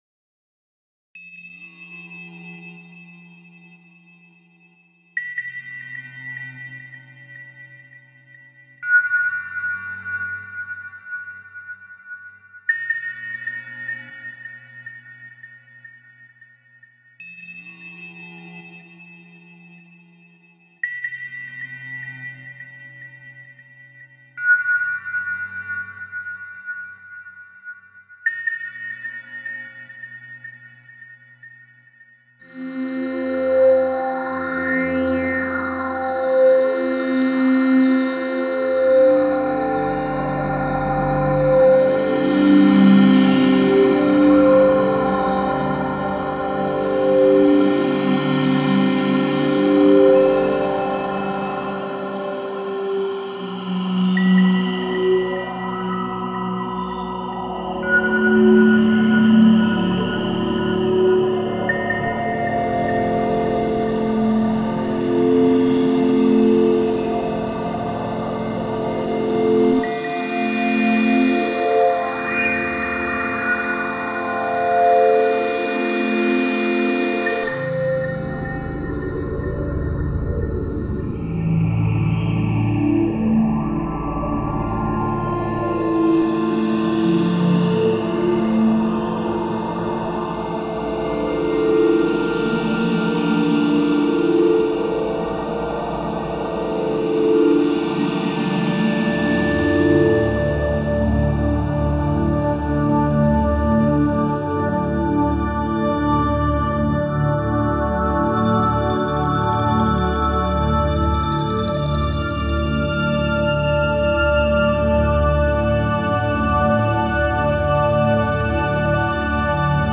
Recorded at Magnetic5280 Studios